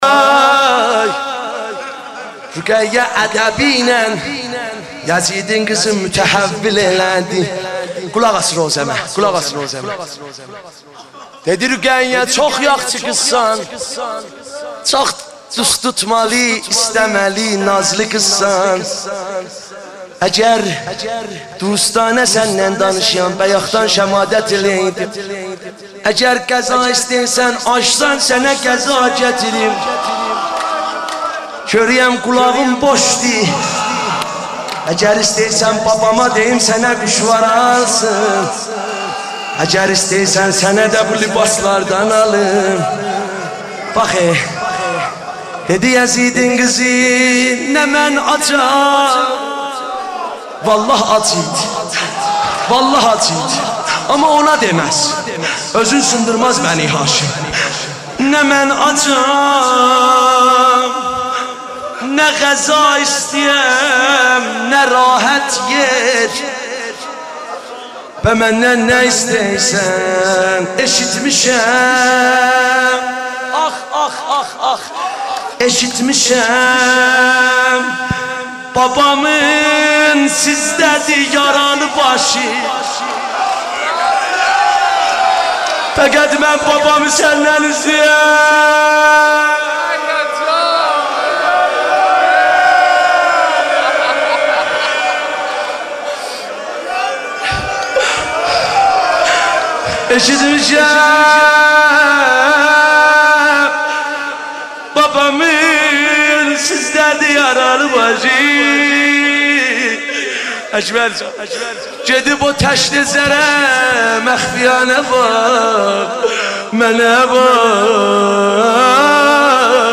شب سوم محرم
مداحی آذری نوحه ترکی